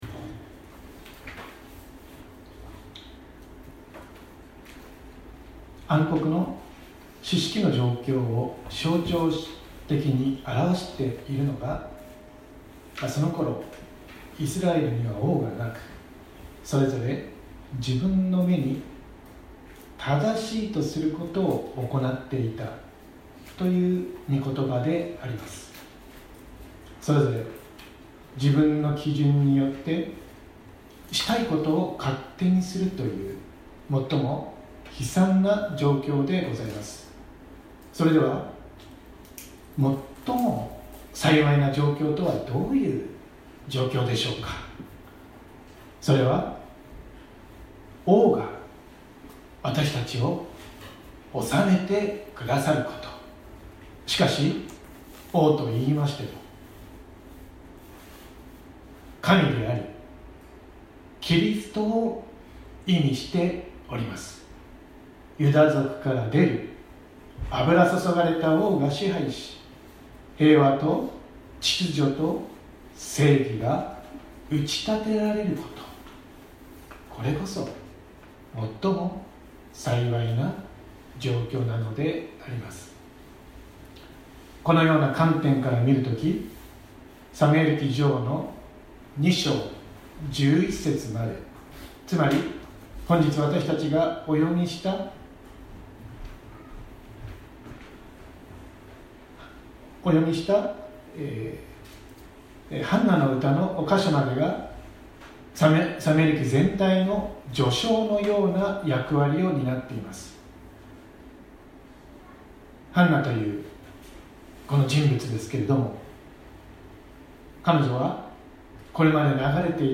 説教アーカイブ。
私たちは毎週日曜日10時20分から12時まで神様に祈りと感謝をささげる礼拝を開いています。